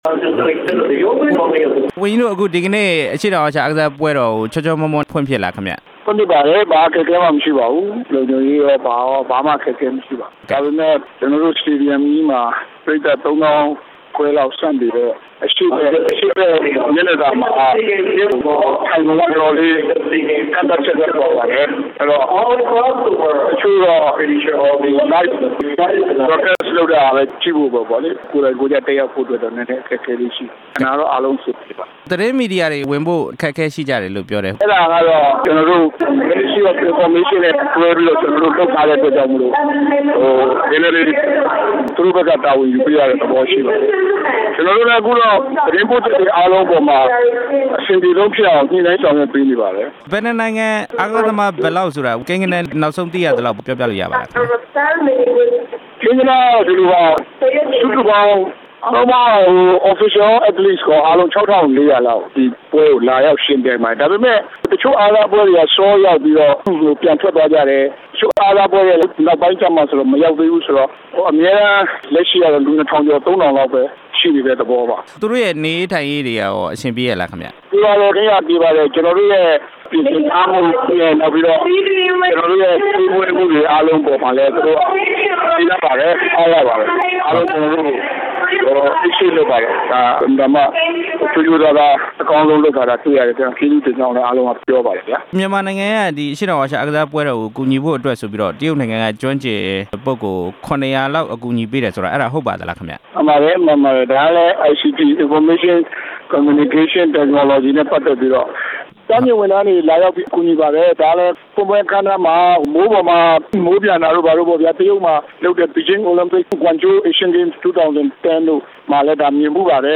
အားကစား ဒုဝန်ကြီး ဦးသောင်းထိုက်နဲ့ မေးမြန်းချက် နားထောင်ရန်